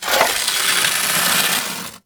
hand mining